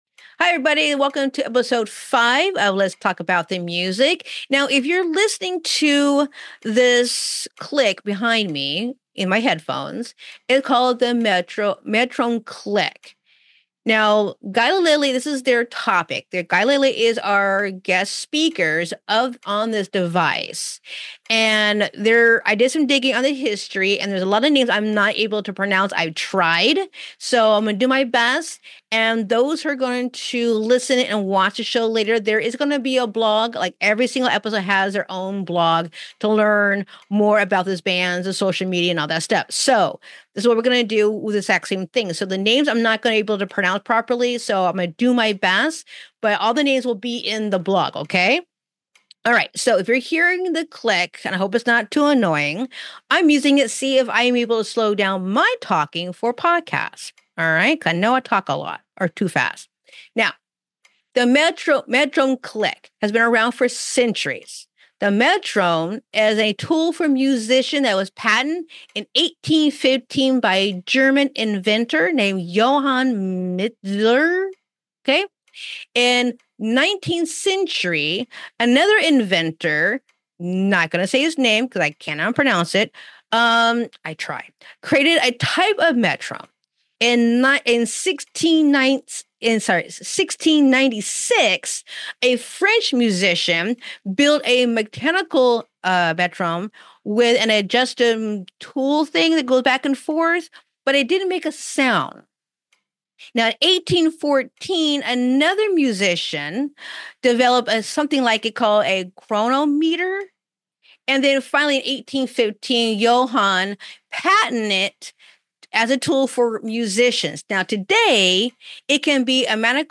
Guest: Guilded Lilly